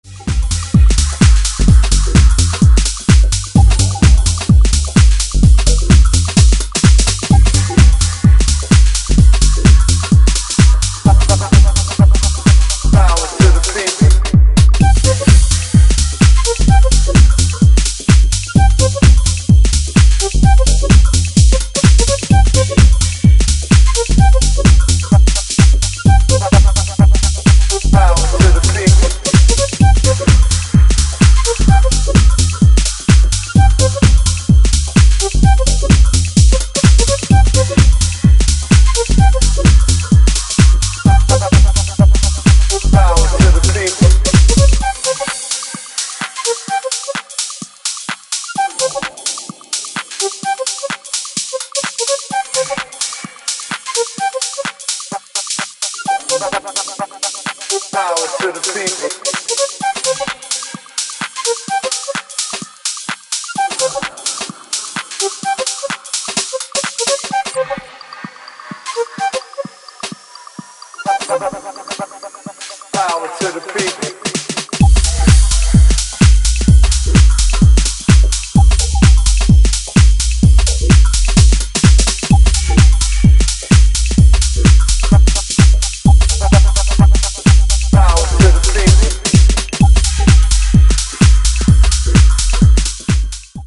ジャンル(スタイル) TECH HOUSE / DEEP HOUSE